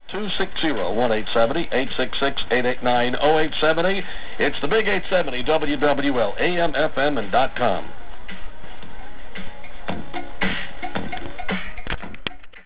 This WAV demonstrates sensitivity; WWL is 460 miles away, coming through loud and clear.